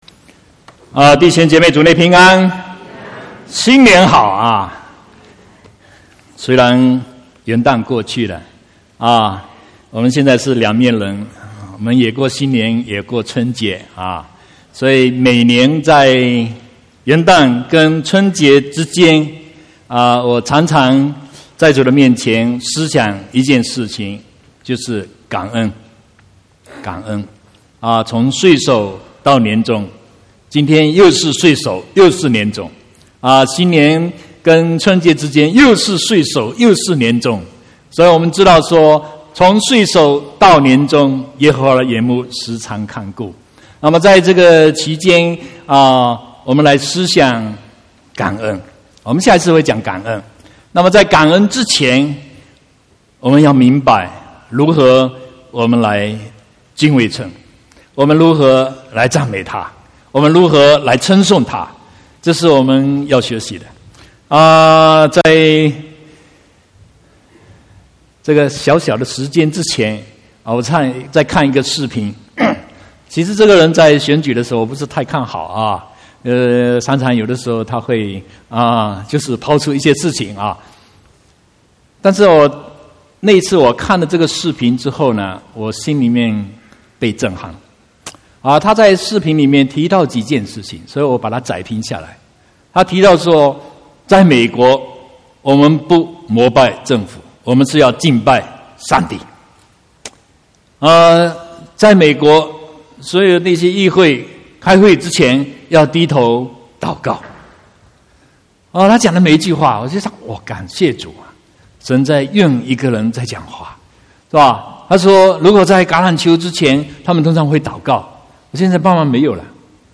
14/1/2018 國語堂講道